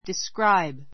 describe A1 diskráib ディ スク ラ イ ブ 動詞 （人・物事がどんな風かを） 述べる , 描写 びょうしゃ する , 言い表す description Can you describe your father's appearance?—Yes, he is tall with glasses and a beard.